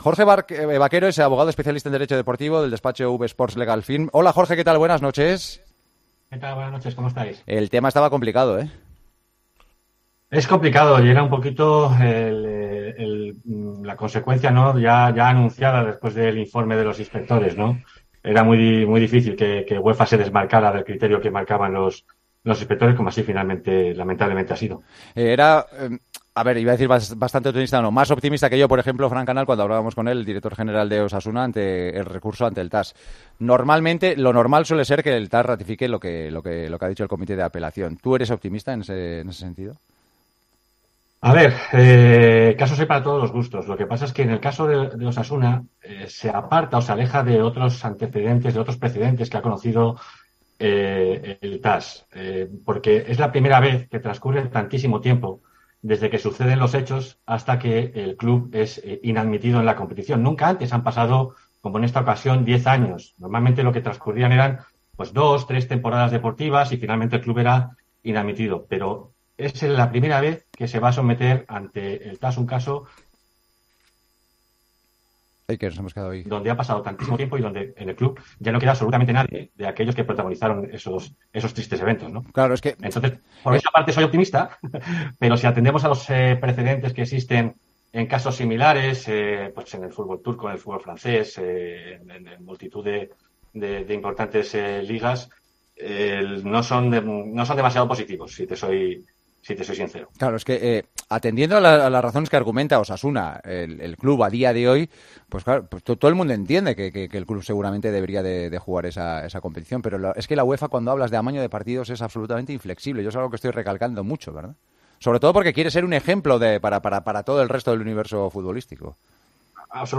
AUDIO: Los tertulianos de El Partidazo de COPE creen que la decisión tomada por el Comité de Apelación de la UEFA "no es justa".